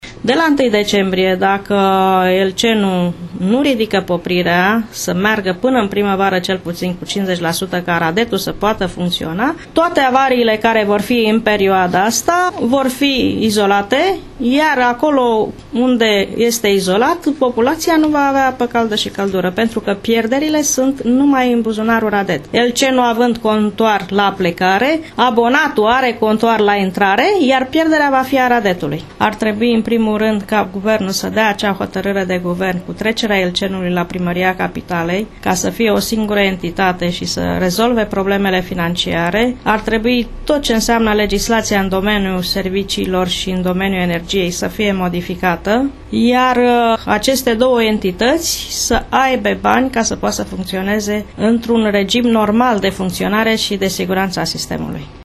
într-o conferință de presă